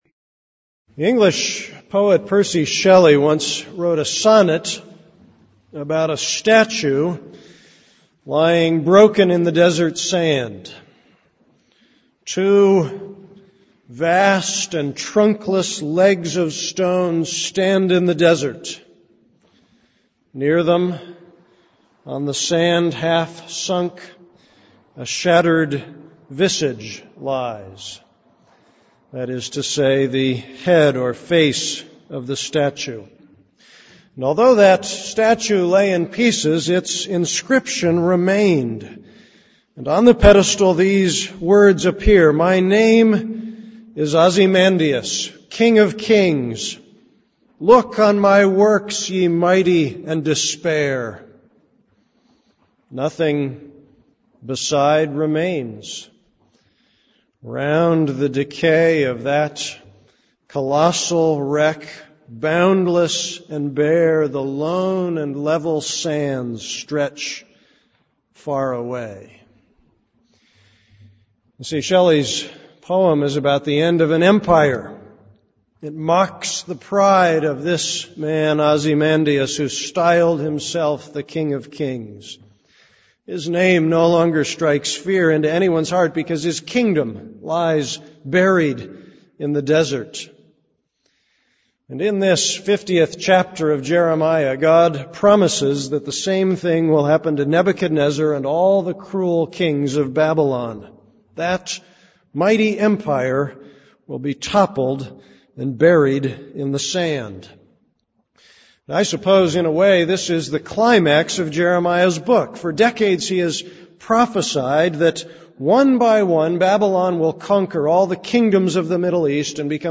This is a sermon on Jeremiah 50:1-20, 33.